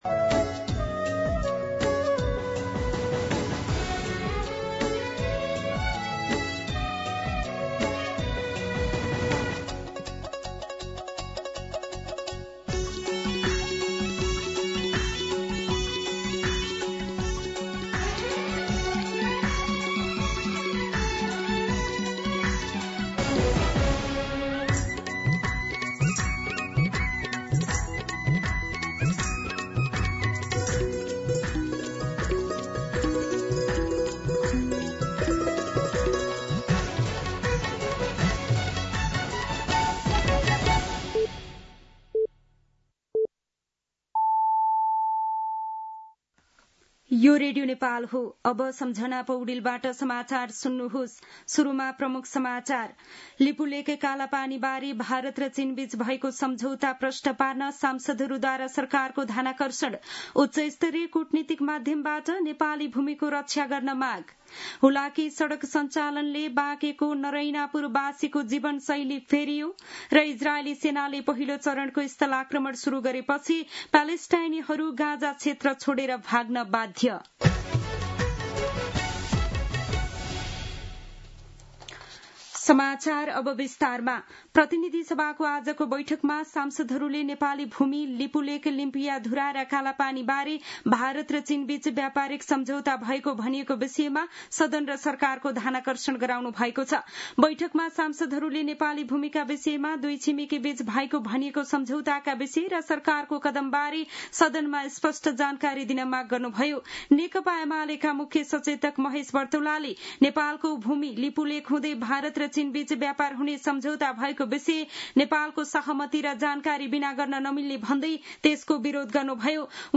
दिउँसो ३ बजेको नेपाली समाचार : ५ भदौ , २०८२